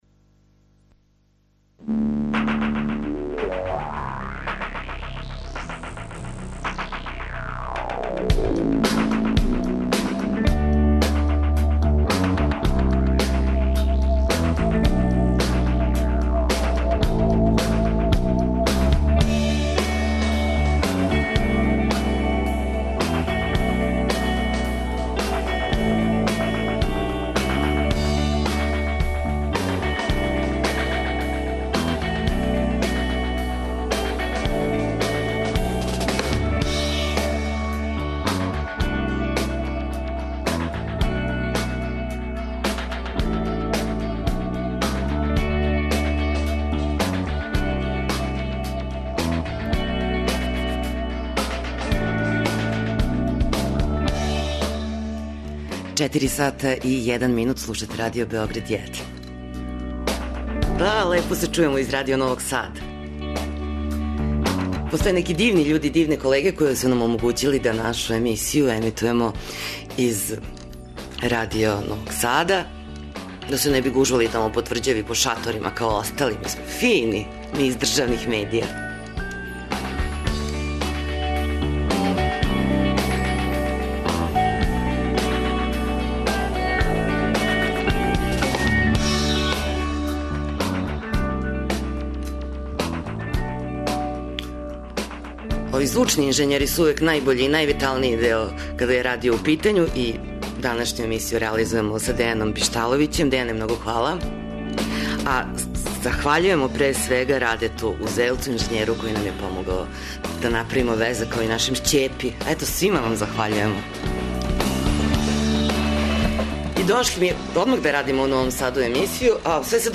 Емисију емитујемо из Новог Сада, где се од 7. до 10. јула одржава Међународни музички фестивал 'Еxit'.